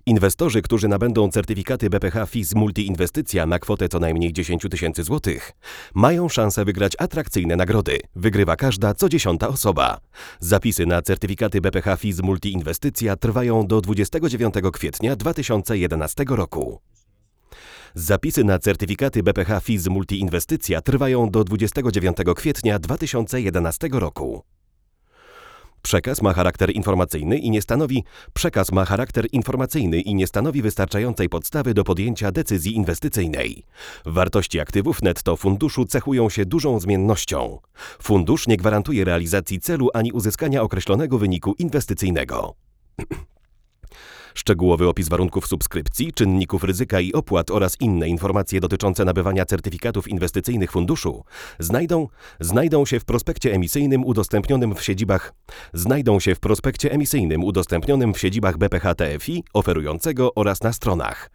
Neumann U89 + Pre-73
U89 jest, jak się można było spodziewać, dużo bardziej wycofany, ale również dostał delikatnego podbarwienia, choć wydaje się, że nie stracił w dużym stopniu naturalnego sedna swojego brzmienia.